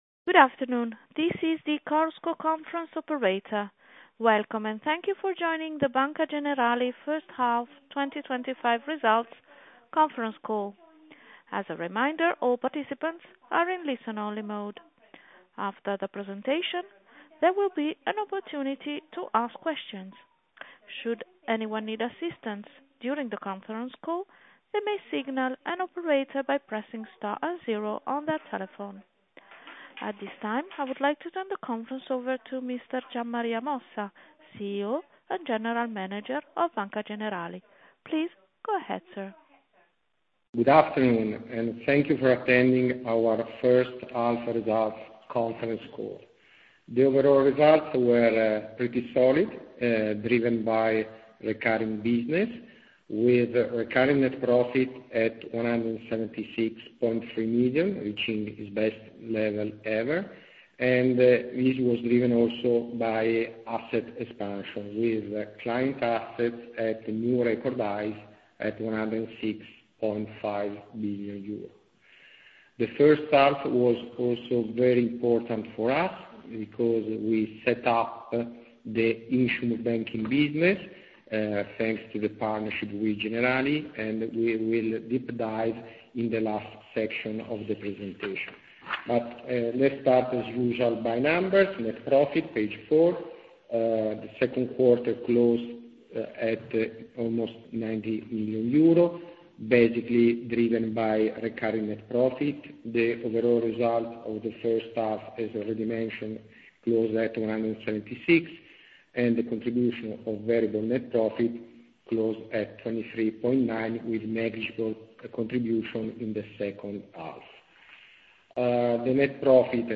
Audio Conference Call 2024 Risultati Preliminari